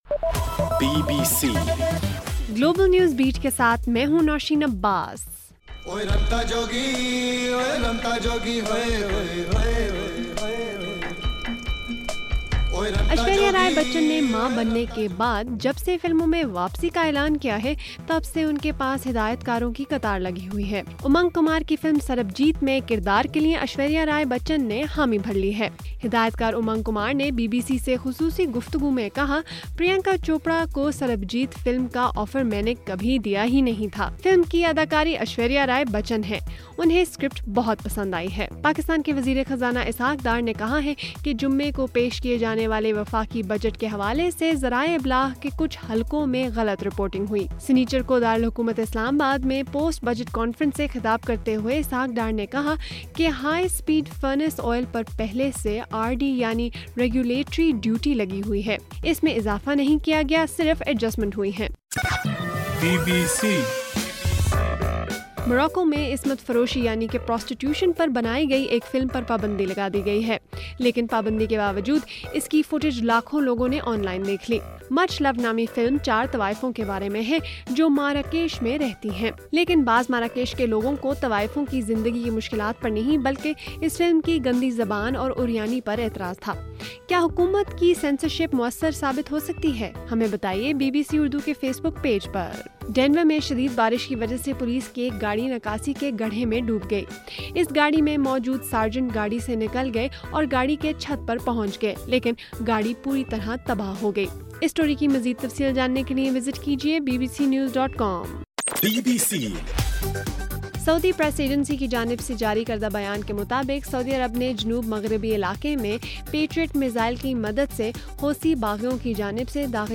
جون 6: رات 10 بجے کا گلوبل نیوز بیٹ بُلیٹن